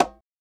SG - Bongo 2.wav